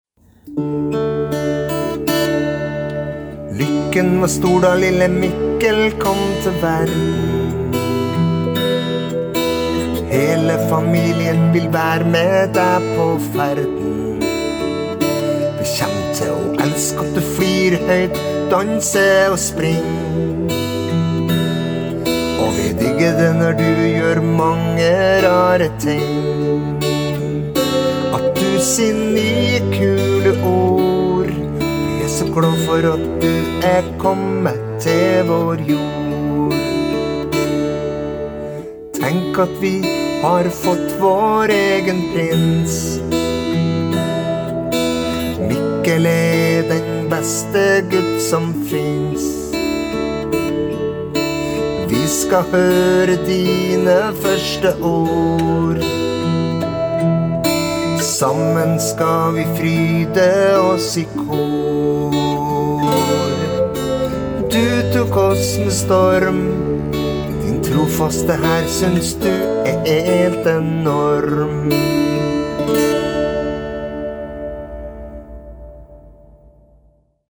Lørdag 18. juni 2022: MIKKEL – BARNESANG